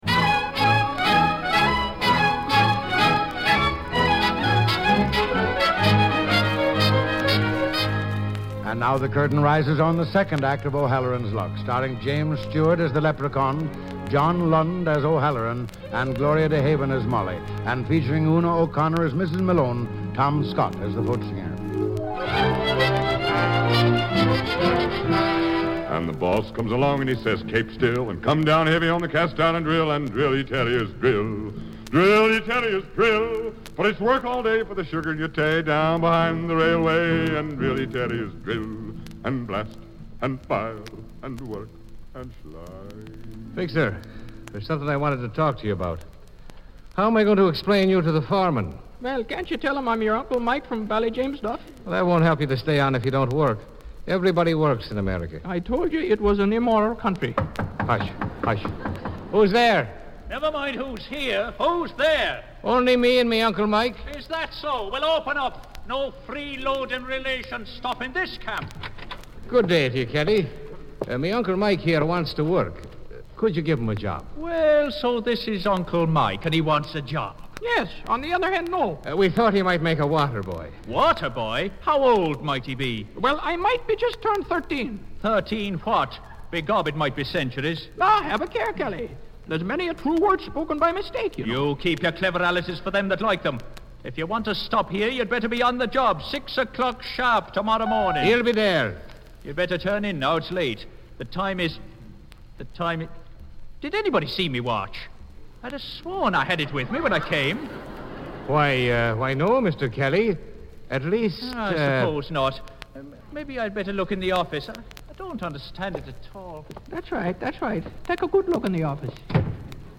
The 6-foot-3-inch Stewart played the role of a leprechaun! Starring with him were John Lund, Gloria DeHaven and Una O’Connor.
For years, this show was believed to be lost, but through an incredible amount of luck, we located a very good copy.
He did…an AFRS transcription disc of O’Halloran’s Luck .